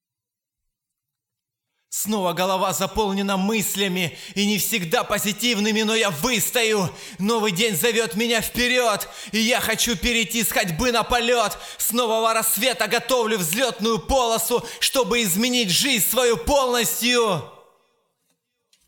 Явно слышен ход часов. Слышны голоса в соседнем помещении.